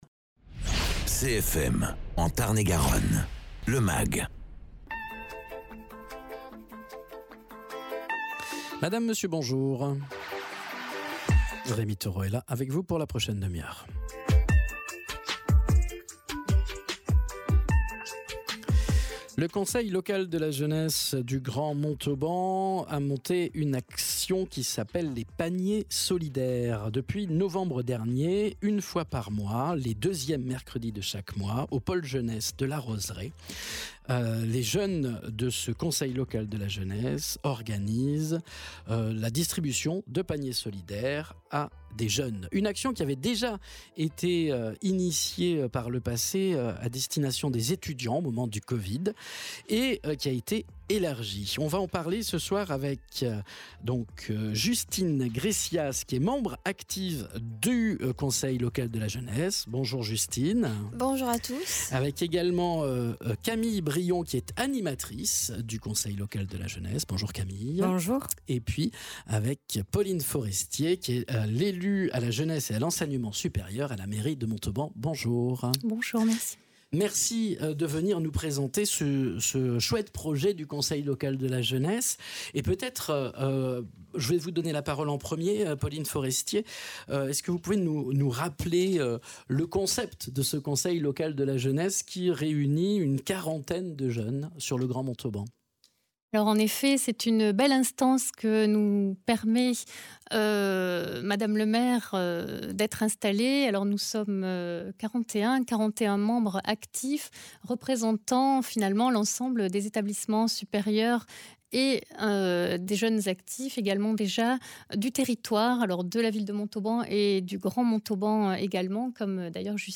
Invité(s) : Pauline Forestier, élue en charge de la jeunesse et de l’enseignement supérieur à la mairie de Montauban;